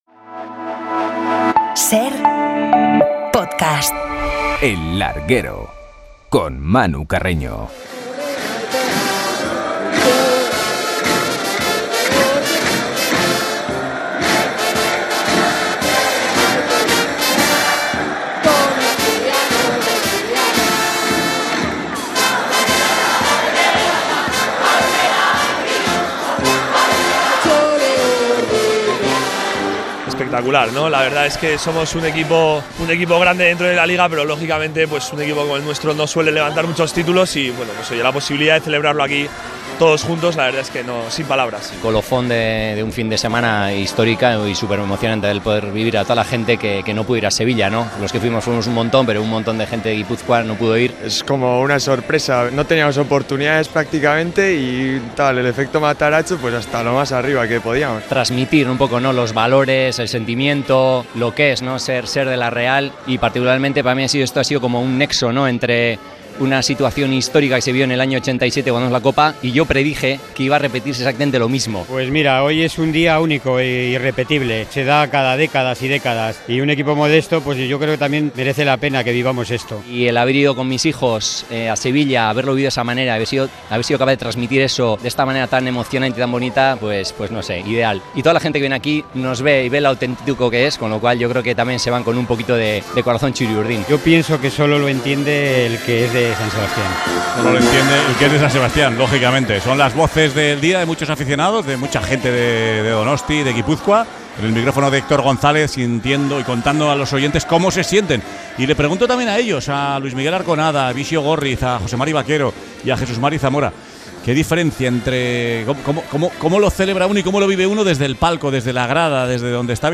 Bixio Górriz, Luis Arconada, Jesús Mari Zamora y José Mari Bakero, invitados de lujo en 'El Larguero'